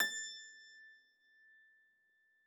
53j-pno21-A4.wav